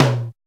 626 TOM2 MD.wav